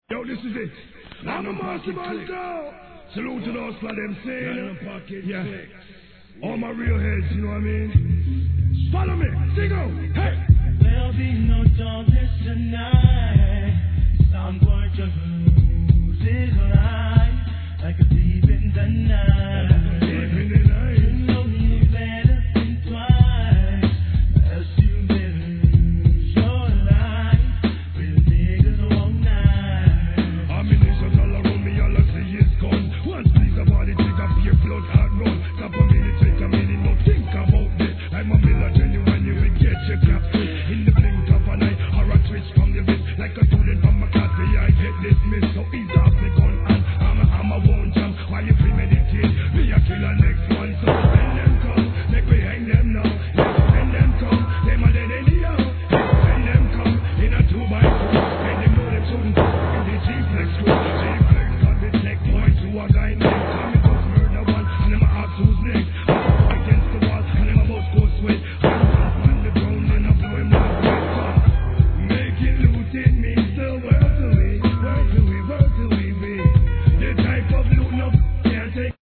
HIP HOP/R&B
不穏なピアノのメロディーがDOPE!